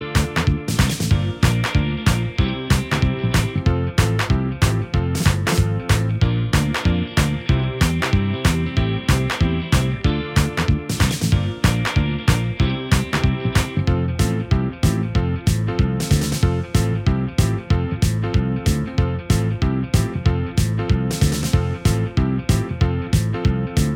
Minus Lead Guitars Pop (1980s) 2:13 Buy £1.50